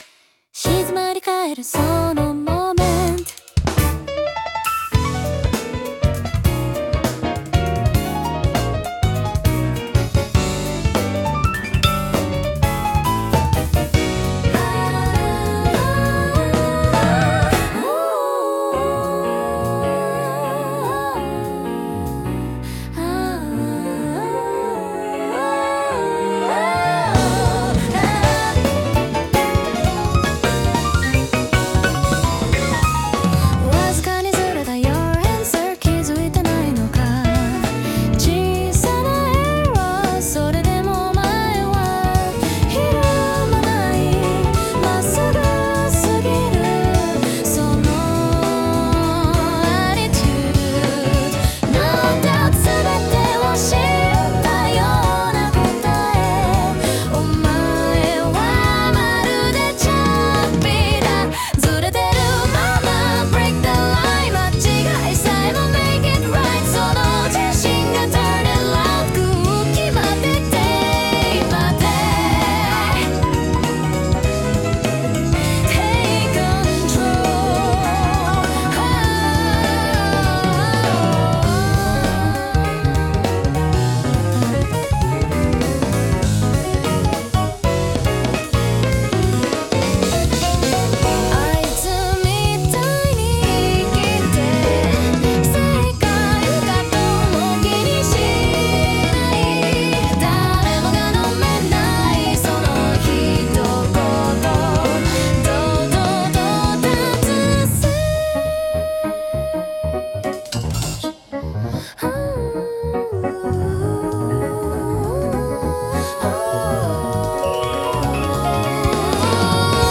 女性ボーカル
イメージ：プログレッシブ・ポップ,女性ボーカル,ピアノアルペジオ,ジャズフュージョン